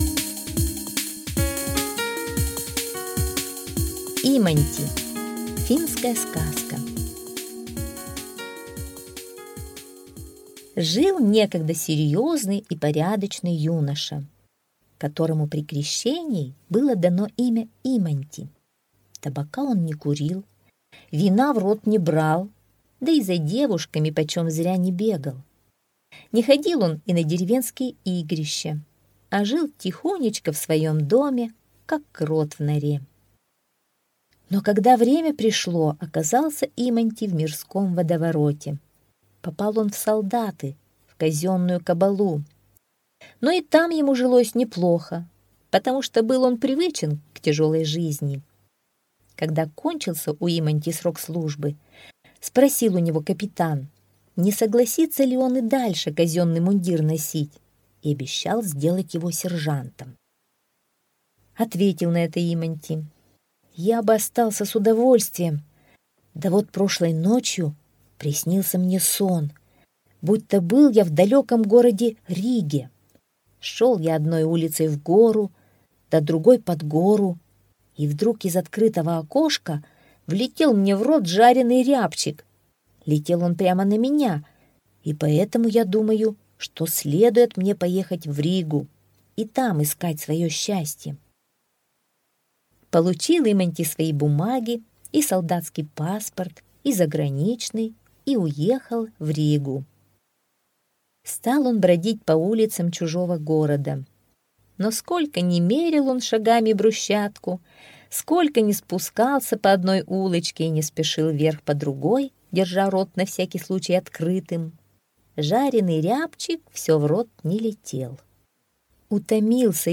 Иманти - финская аудиосказка - слушать онлайн